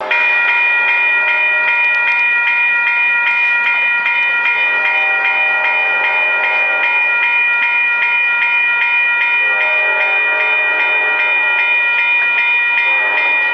train crossing signal
crossing locomotive rail railroad sign signal train warning sound effect free sound royalty free Voices